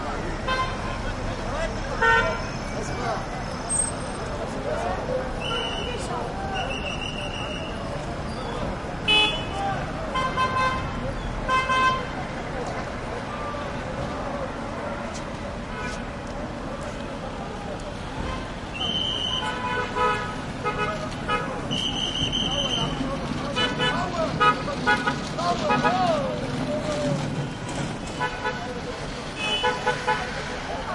印度 " 街道上忙碌的人们袅袅婷婷的交通持续不断的喇叭声选举集会+灯光欢呼结束印度
描述：街头忙碌的人们嘶哑的交通持续的喇叭鸣喇叭选举集会+光欢呼结束India.flac
Tag: 交通 街道 欢呼 喇叭 印度 honks 沙哑